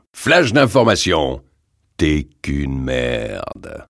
Tags: Duke Nukem sounds quotes Dukenukem Ultimate Ultime francais france repliques sons bruits voix phrases